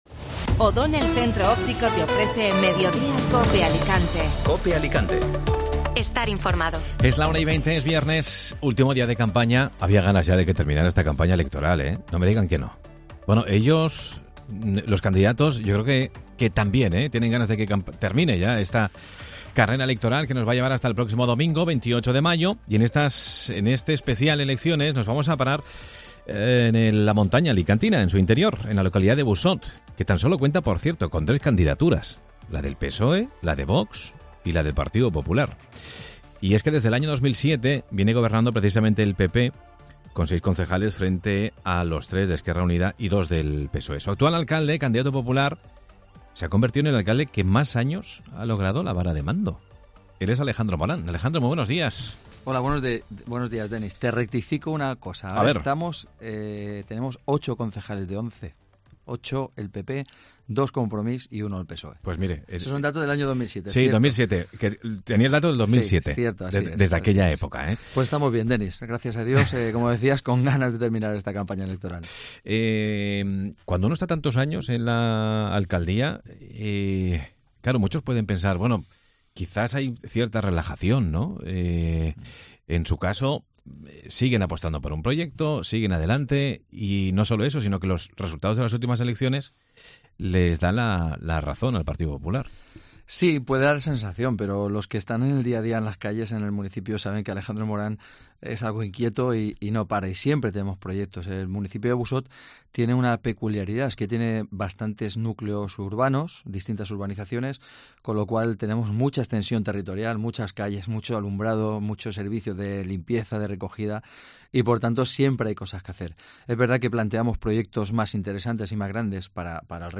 Entrevista a Alejandro Morant, candidato PP a la alcaldía de Busot